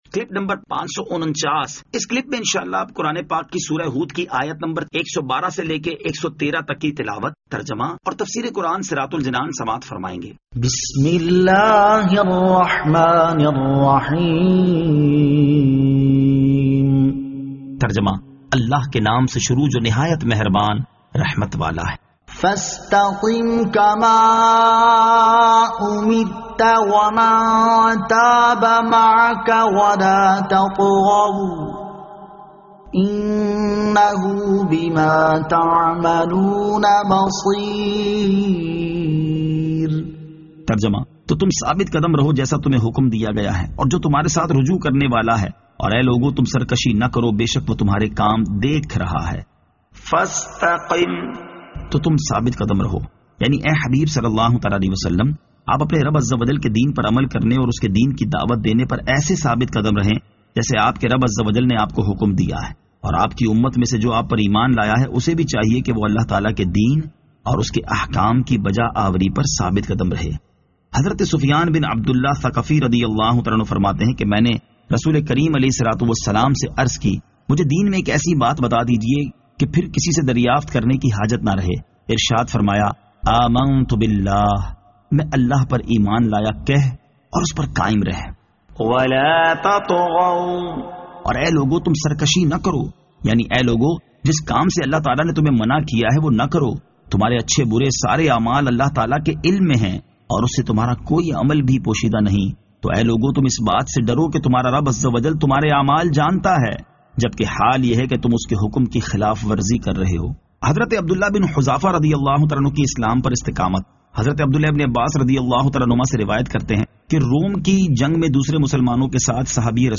Surah Hud Ayat 112 To 113 Tilawat , Tarjama , Tafseer
2021 MP3 MP4 MP4 Share سُوَّرۃُ ھُوٗد آیت 112 تا 113 تلاوت ، ترجمہ ، تفسیر ۔